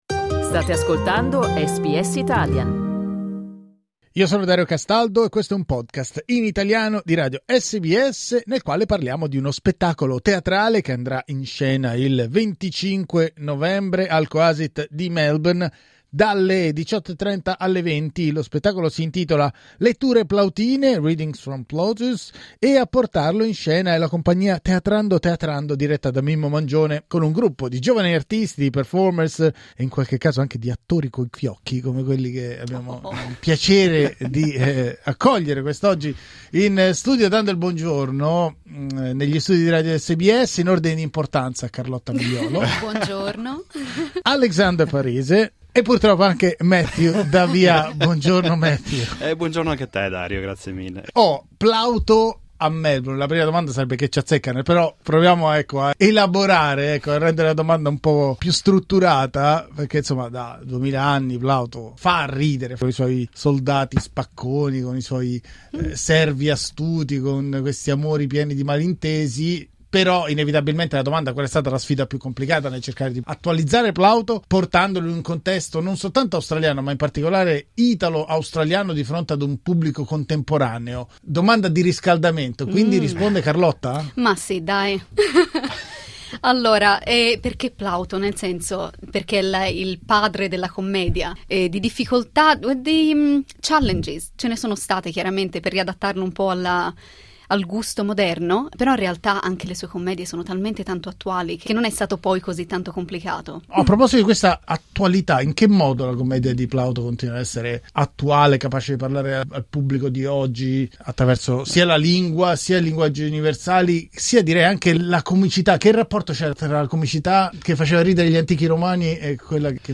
Clicca sul tasto "play" per ascoltare la nostra intervista agli attori di Teatrando Teatrando